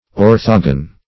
Search Result for " orthogon" : The Collaborative International Dictionary of English v.0.48: Orthogon \Or"tho*gon\, n. [Ortho- + Gr. gwni`a angle: cf. F. orthogone, a.]